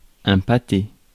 Ääntäminen
US : IPA : [ˈblɑːt]